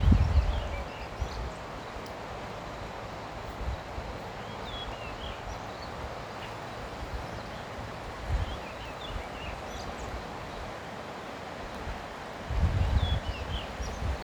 Turdus chiguanco anthracinus
Nombre en inglés: Chiguanco Thrush
Localidad o área protegida: Cañon del Atuel
Condición: Silvestre
Certeza: Observada, Vocalización Grabada
Zorzal-Chiguanco_2.mp3